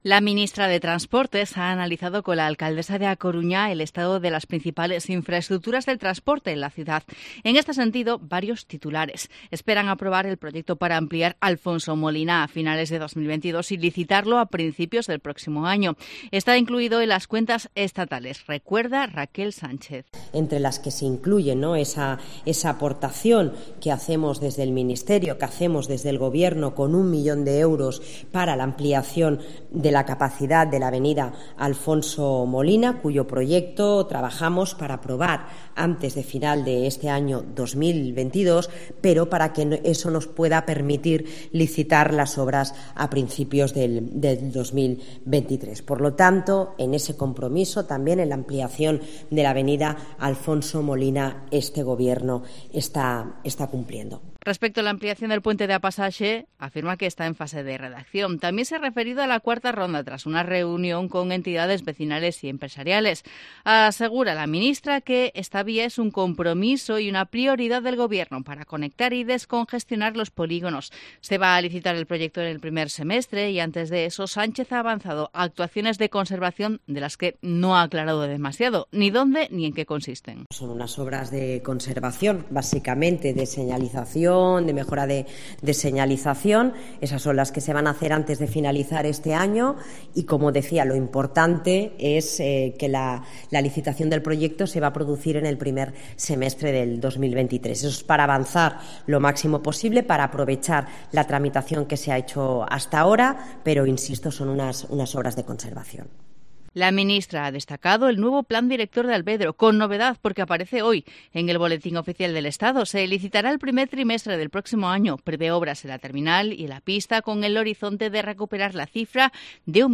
Crónica de la visita y los anuncios de la ministra de Transportes a A Coruña